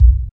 5209R BD.wav